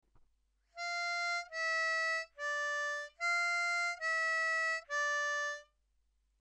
It’s a Sea Shanty sung by Sailors and Pirates.
We have chopped the tune up into small chunks to help you.